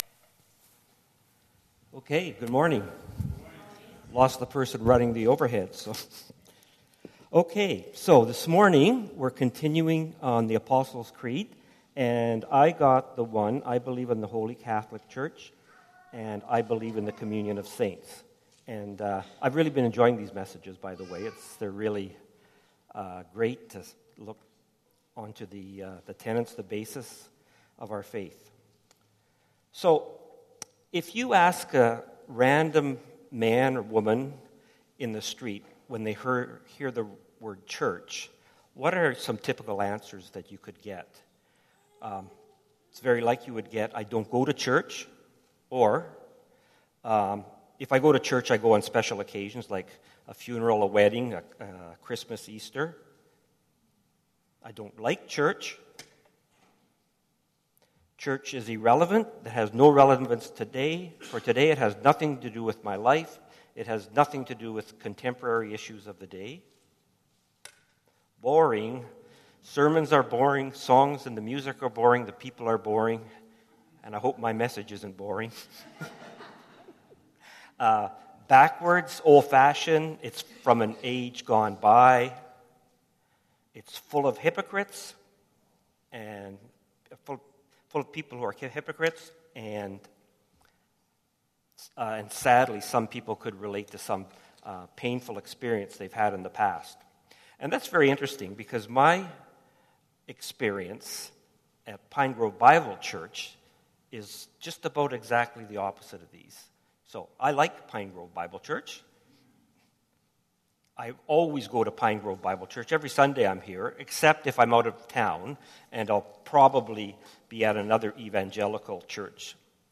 PG Sermons